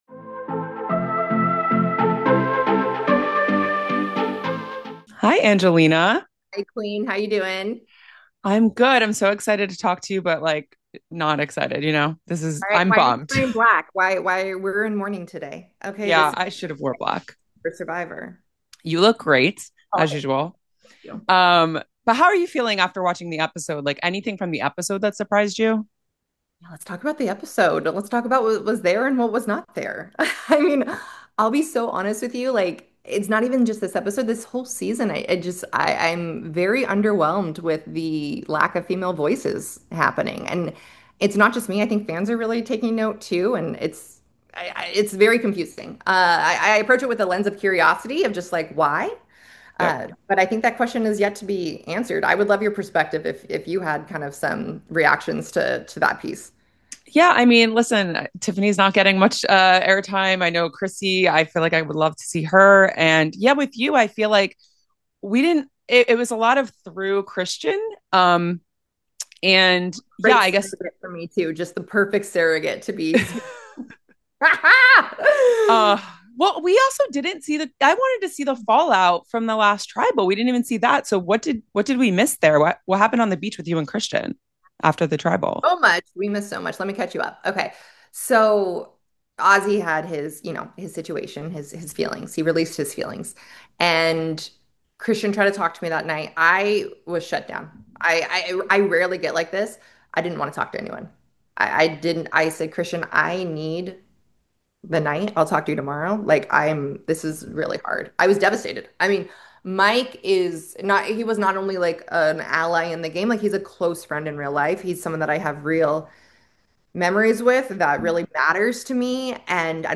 Survivor 50 Exit Interview: 6th Player Voted Out